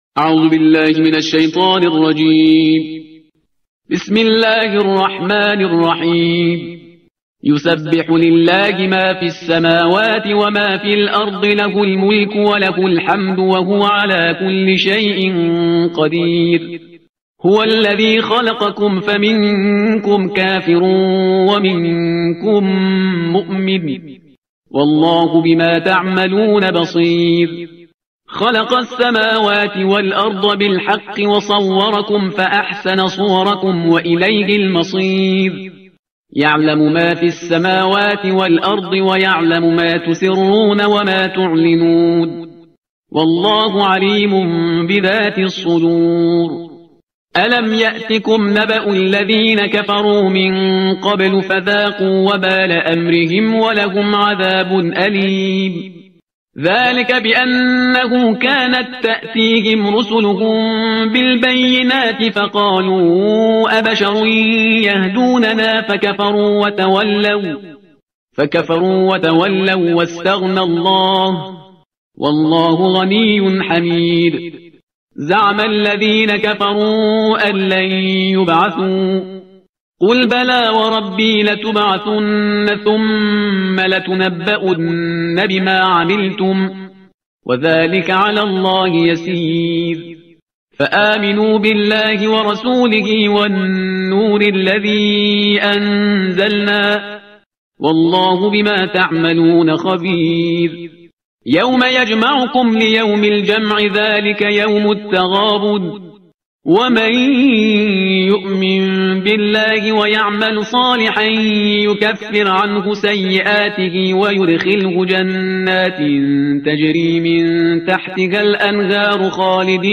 ترتیل صفحه 556 قرآن – جزء بیست و هشتم